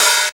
HH HH 75.wav